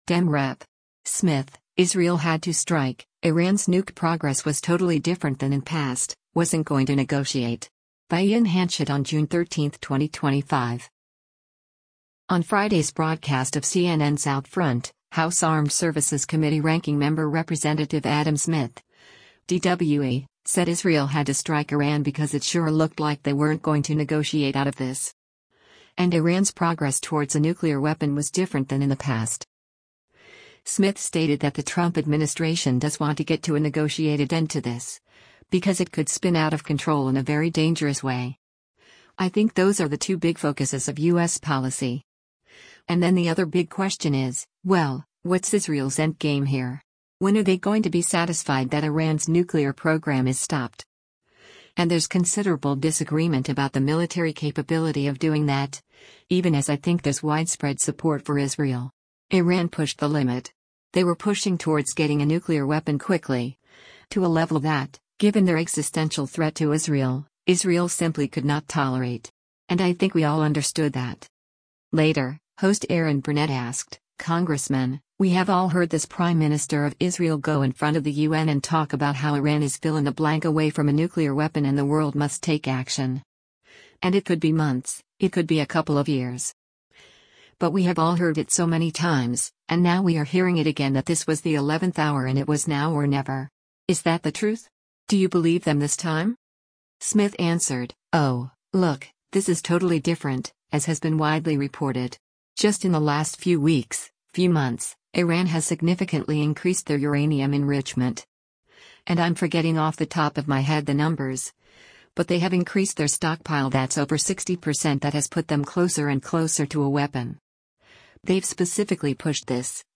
On Friday’s broadcast of CNN’s “OutFront,” House Armed Services Committee Ranking Member Rep. Adam Smith (D-WA) said Israel “had to” strike Iran because “it sure looked like they weren’t going to negotiate out of this.”